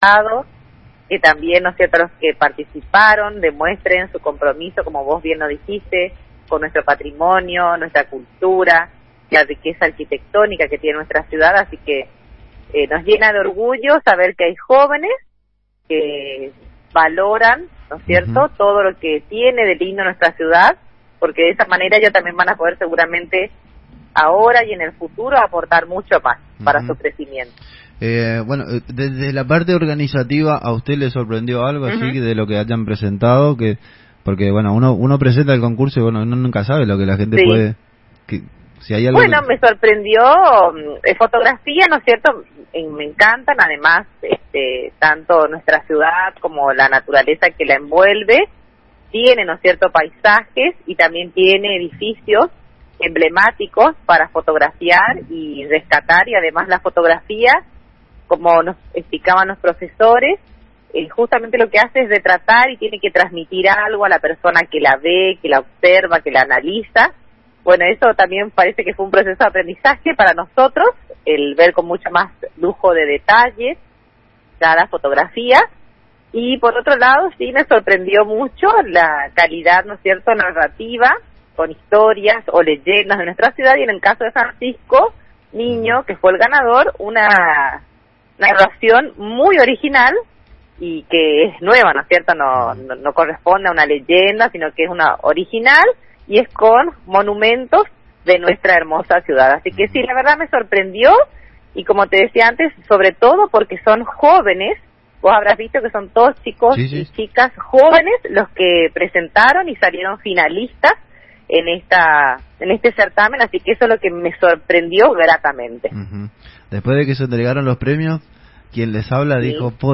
El Radio Chart 89.1 fue entrevistada la presidente del concejo deliberante y dejo un mensaje por el aniversario de la fundación de Mercedes, realzo lo que viene haciendo la gestión y lo que siente al ver el desarrollo de la ciudad.